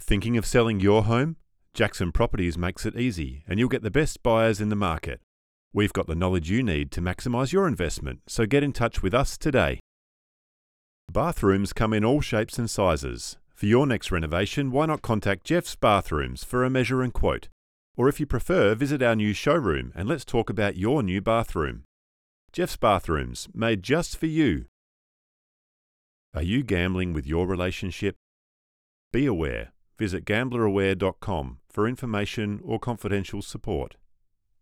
Male
English (Australian)
Deep, comforting, trustworthy, steady voice.
Radio Commercials
Radio Advert Samples
Words that describe my voice are Trustworthy, Calm, Deep.
1228PortfolioDemos-_Advertisements.mp3